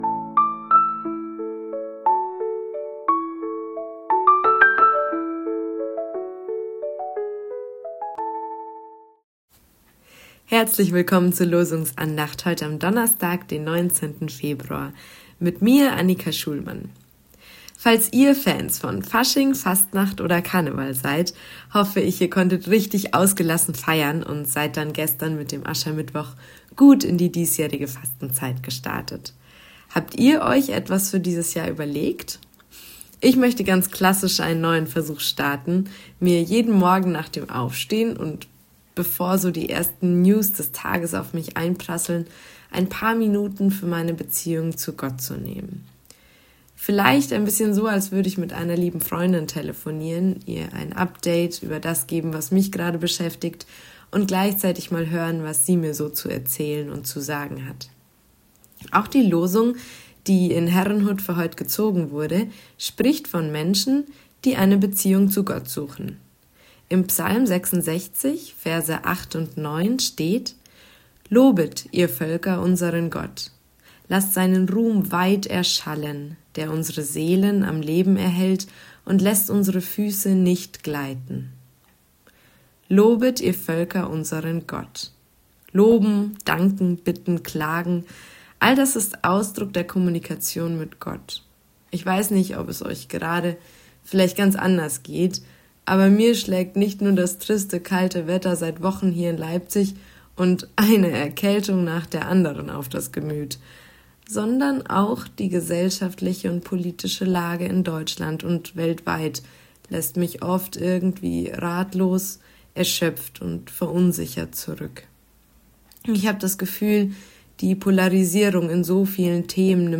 Losungsandacht für Donnerstag, 19.02.2026